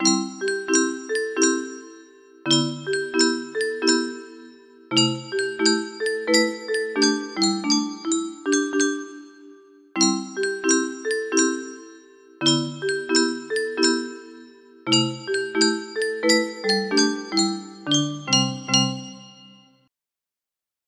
Sleepytime Express music box melody